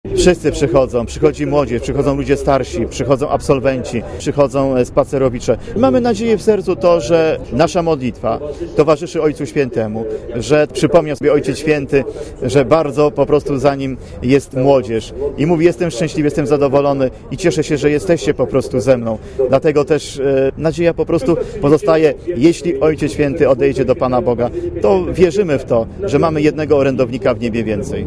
O godzinie 12.00 kościół akademickim św. Anny w Warszawie był wypełniony po brzegi.
modlitwa-sw-anna.mp3